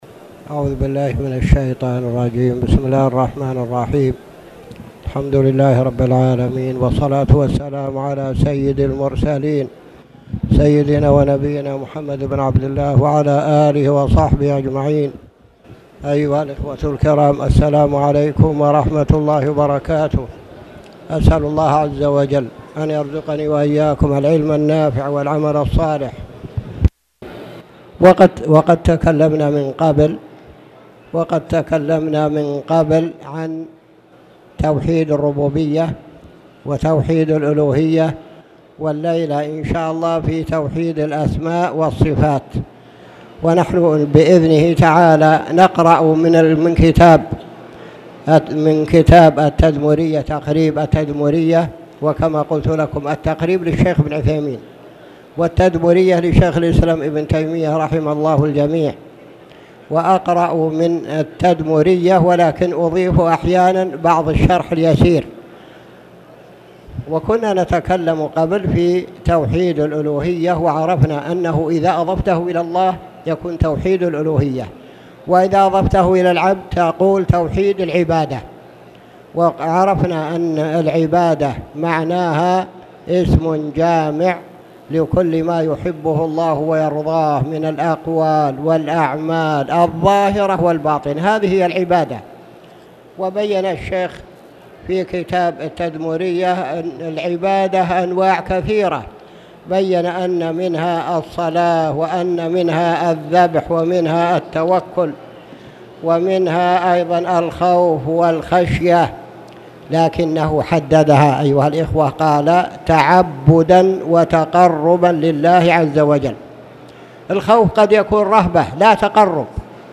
تاريخ النشر ٢١ صفر ١٤٣٨ هـ المكان: المسجد الحرام الشيخ